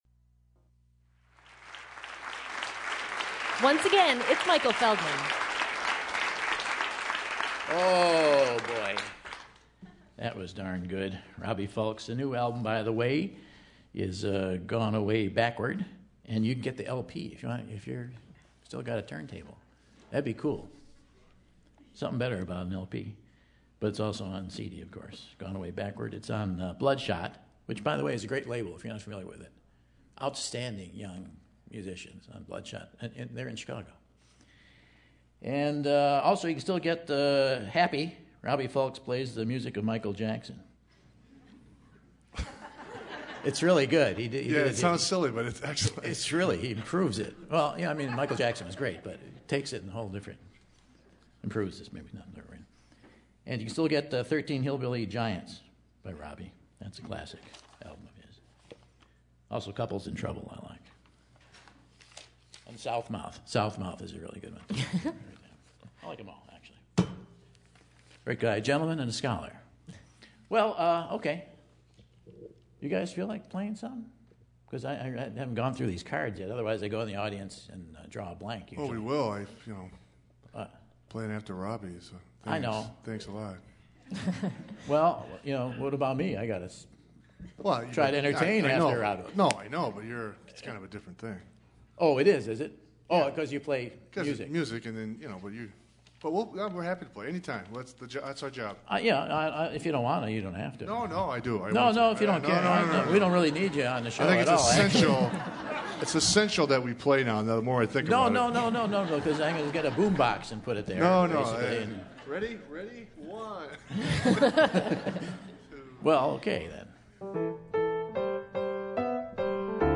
We find out about Michael's morning and pre-show routines, then explains the critical difference between Minnesota or Wisconsin and the Zimmerman (Dylan) family. Then multiple folks in the audience explain sun dogs to Michael.